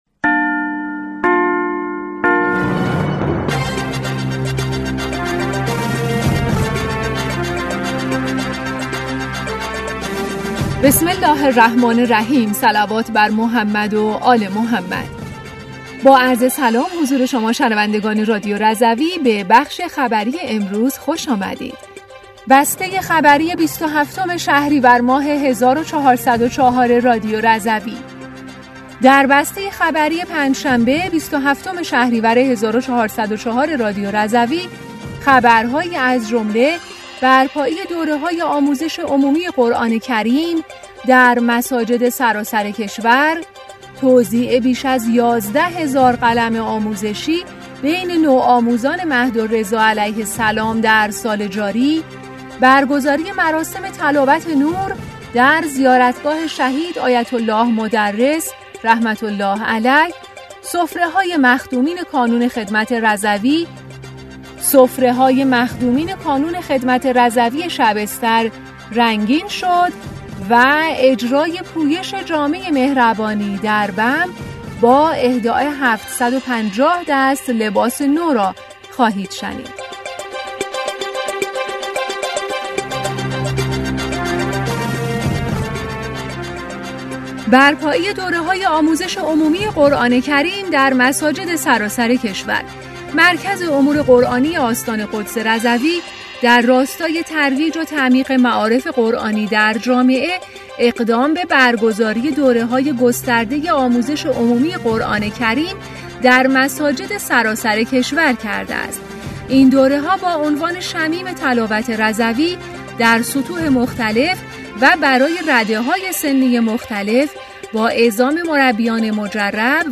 بسته خبری ۲۷ شهریور ۱۴۰۴ رادیو رضوی/